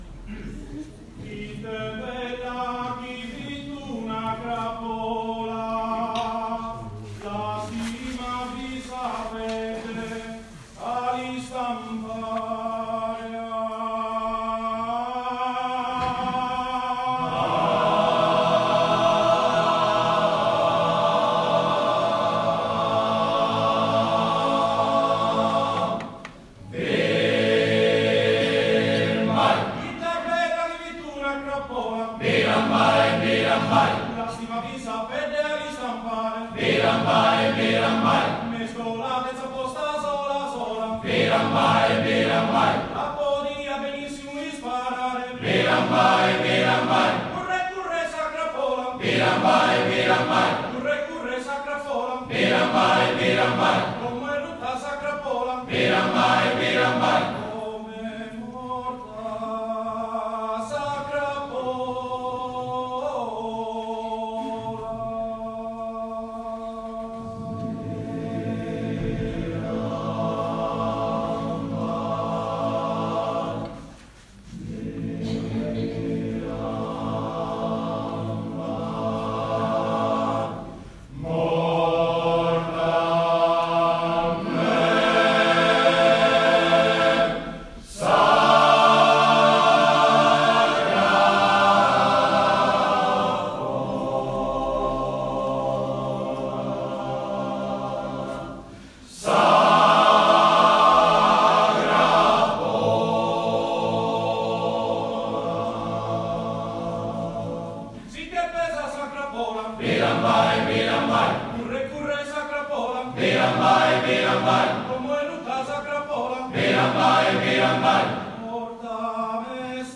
S'Arrodia - Coro Polifonico Maschile Sinnai - Repertorio